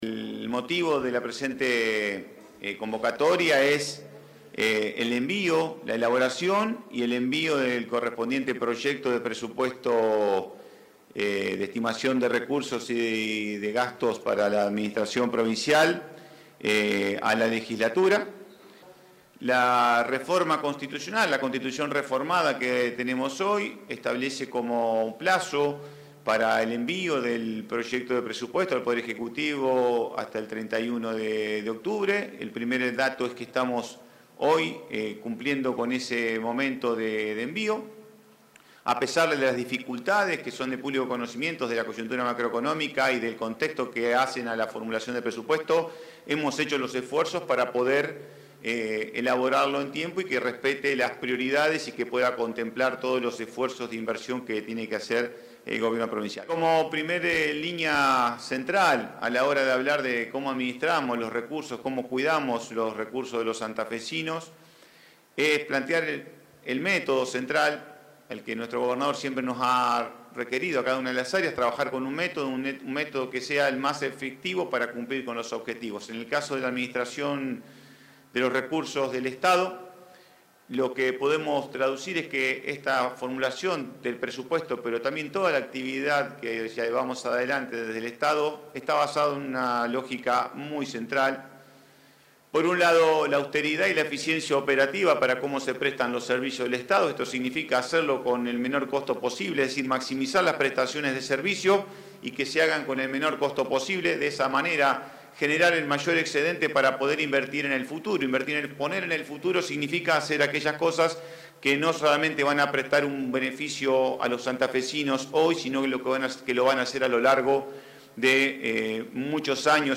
Pablo Olivares, ministro de Economía